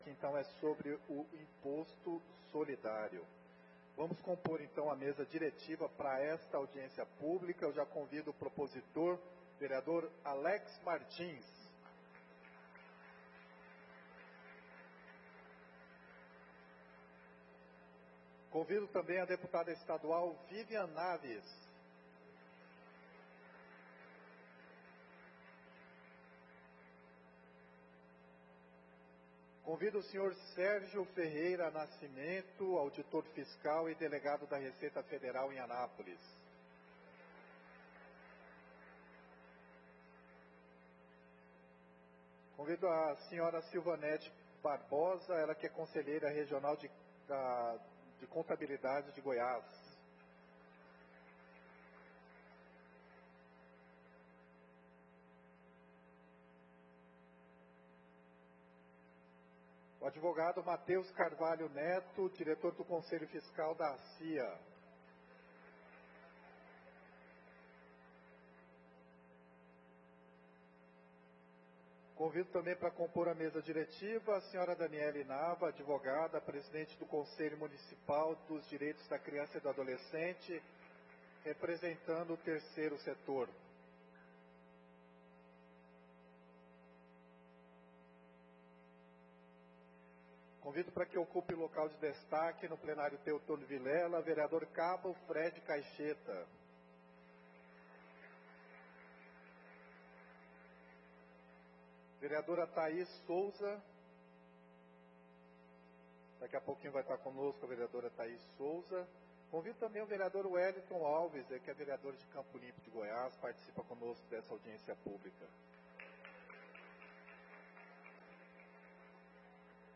Audiência Pública Fundo para infância e Adolescência Dia 08/05/2025